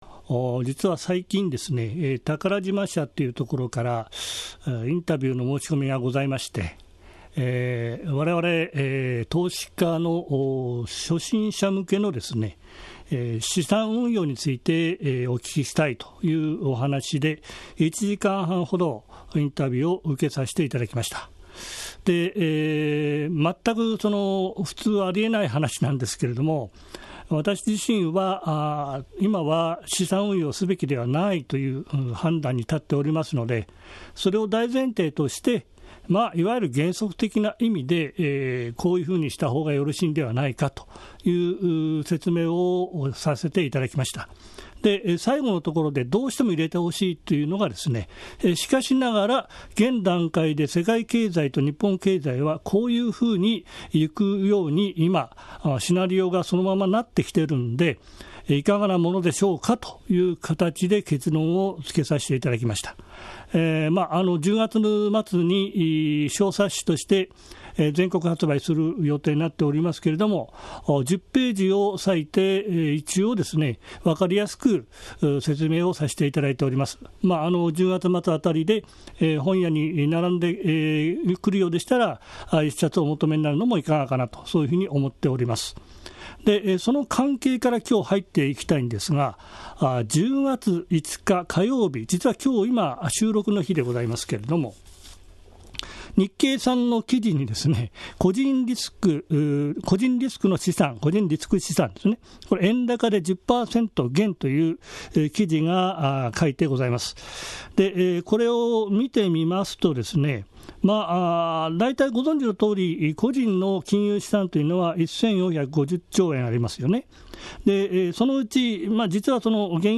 ※ 本製品は音声が収録されたCDです。